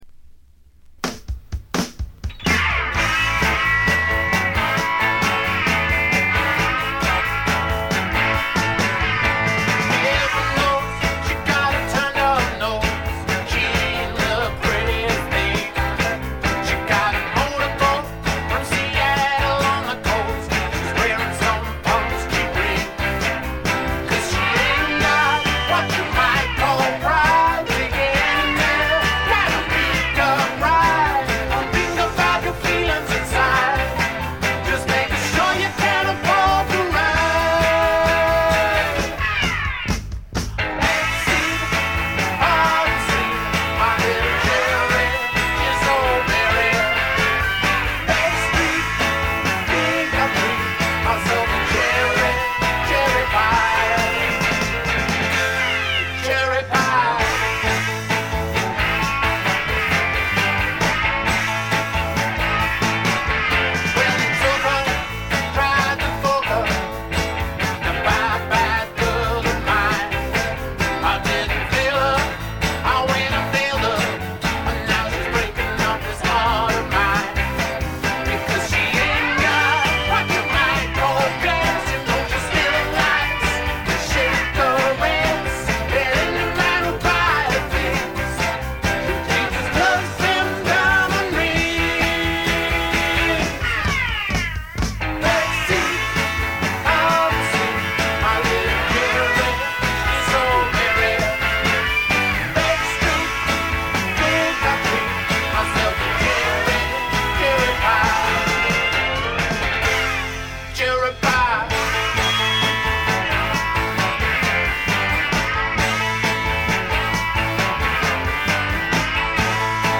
ごくわずかなノイズ感のみ。
ほのかない香るカントリー風味に、何よりも小粋でポップでごきげんなロックンロールが最高です！
試聴曲は現品からの取り込み音源です。
Rockfield Studios, South Wales